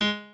pianoadrib1_36.ogg